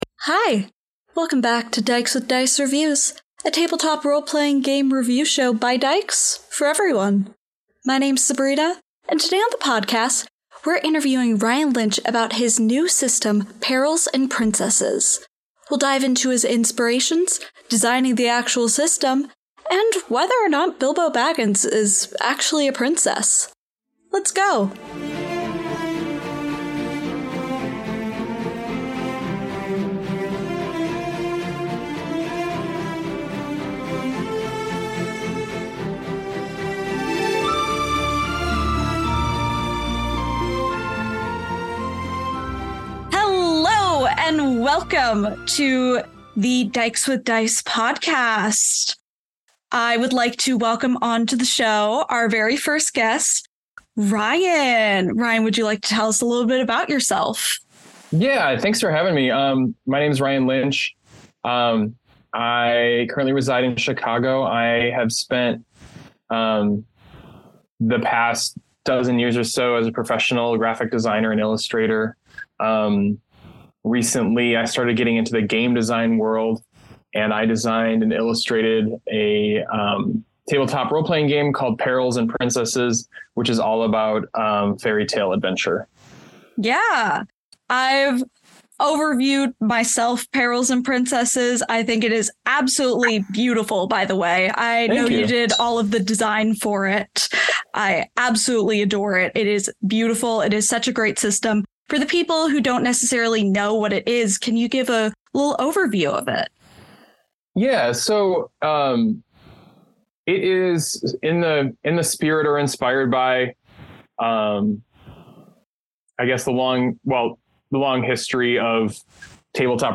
Rating: PG for mild swearing and references to violence.